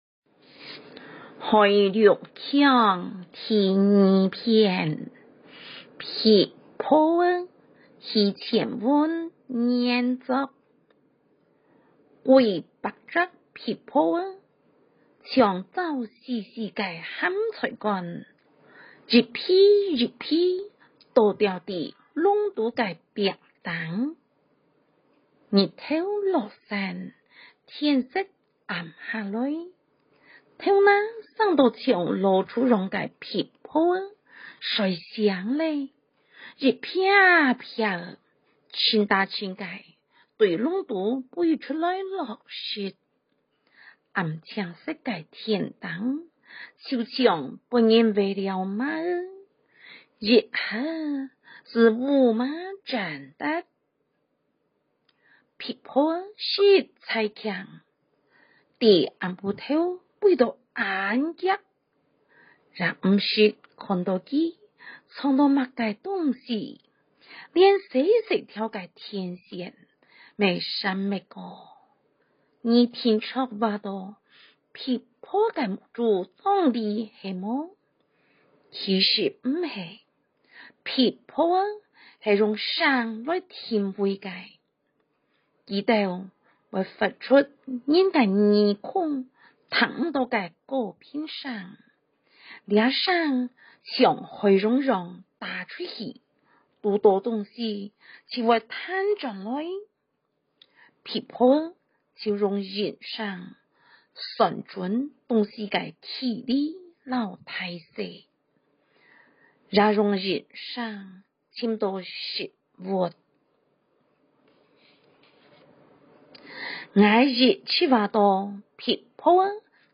113校內語文競賽-客語朗讀(文章、錄音檔)
四縣腔 海陸腔